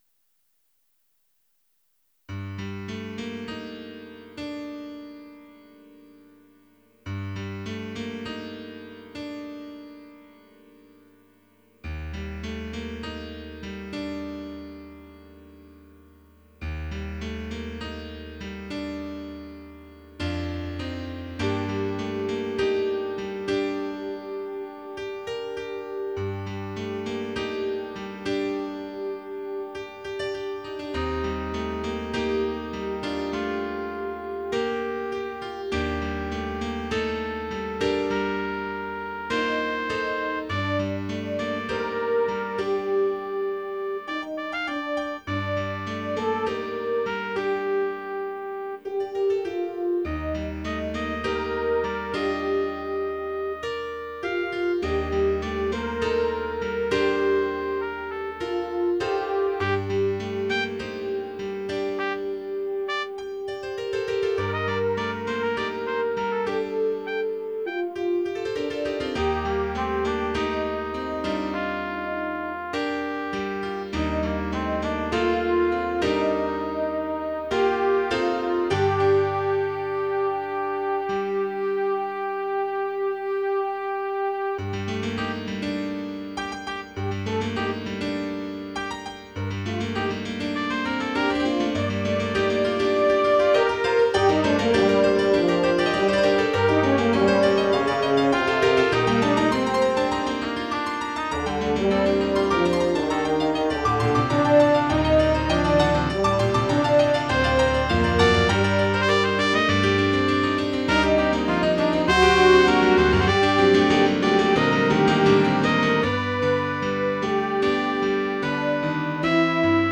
It doesn’t help that I can’t even figure out which category it fits into. mp3 download wav download Files: mp3 wav Tags: Trio, Piano, Brass Plays: 1967 Likes: 0